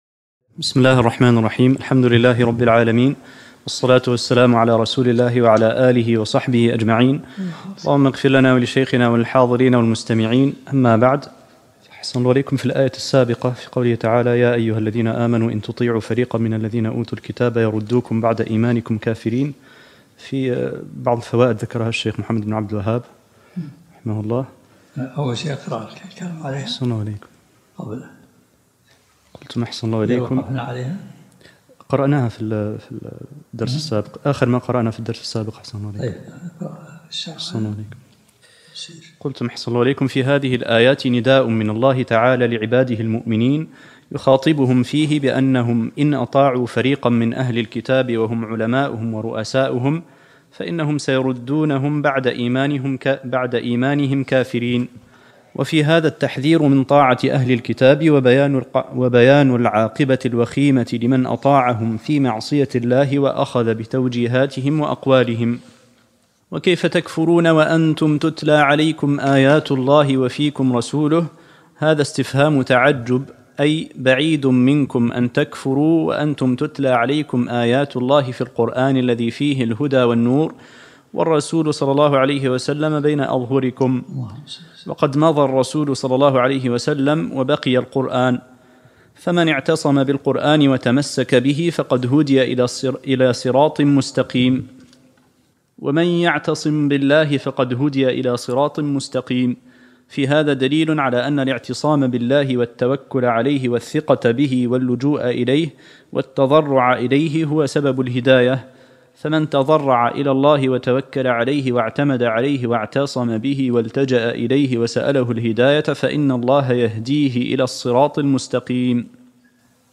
الدروس العلمية